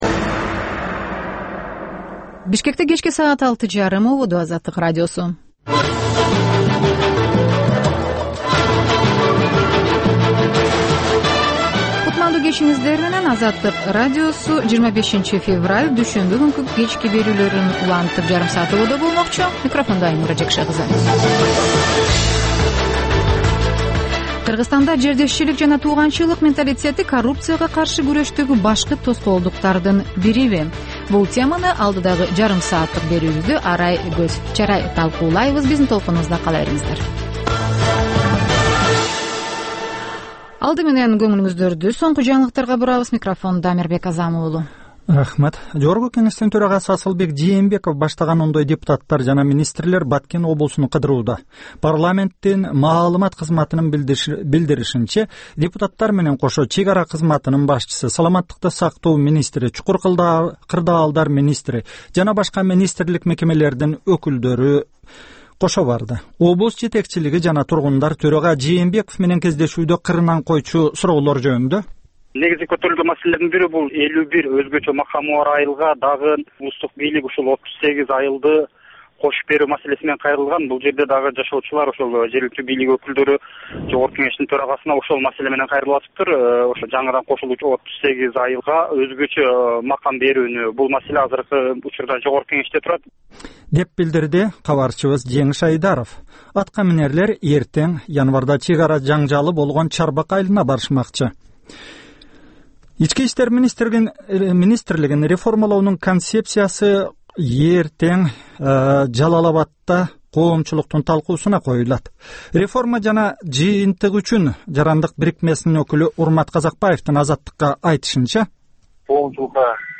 "Азаттык үналгысынын" бул кечки жарым сааттык экинчи берүүсүнүн кайталоосу «Коом жана турмуш» түрмөгүнүн алкагындагы тегерек үстөл баарлашуусу, репортаж, маек, талкуу, аналитикалык баян, сереп, угармандардын ой-пикирлери, окурмандардын э-кат аркылуу келген пикирлеринин жалпыламасы жана башка берүүлөрдөн турат.